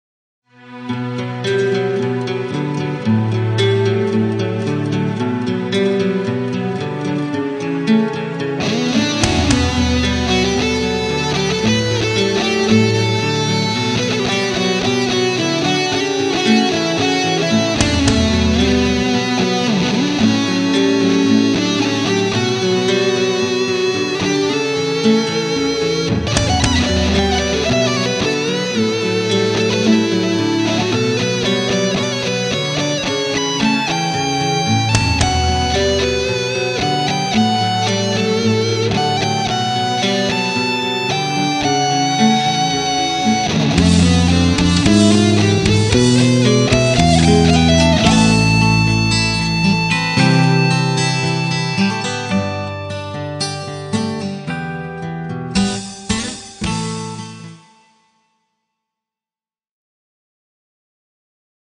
• The backing track I found on Internet.